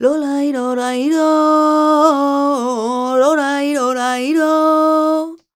46a01voc-g#m.wav